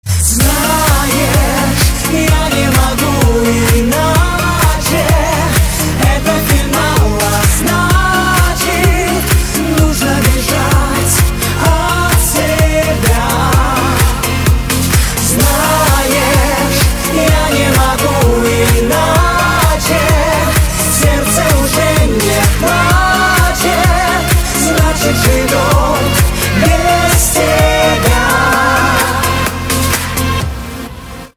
• Качество: 160, Stereo
поп
громкие
женский вокал